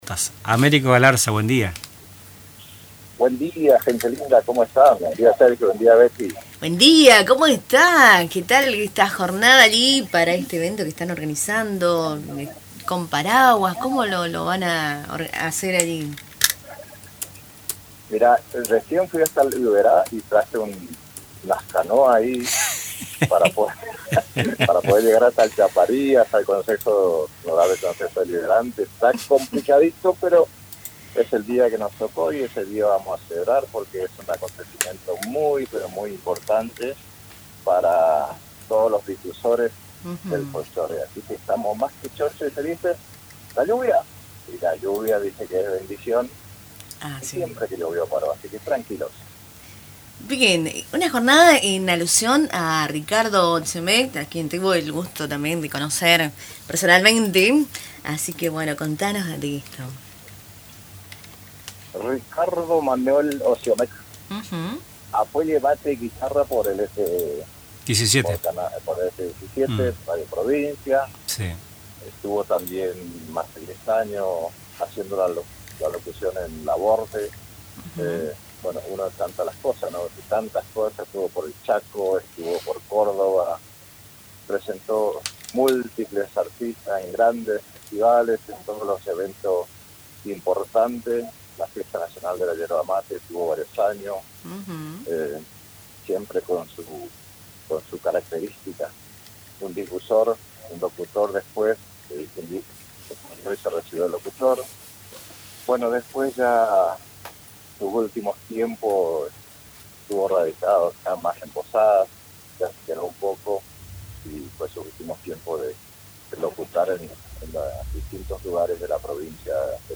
En una entrevista exclusiva con Radio Tupa Mbae